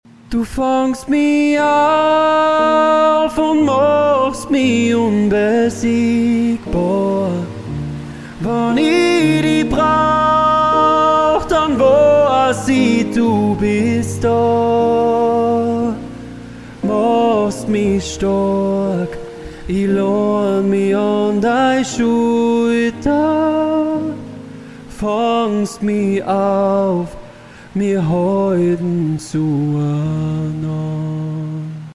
im österreichischen Dialekt!
Austro-Pop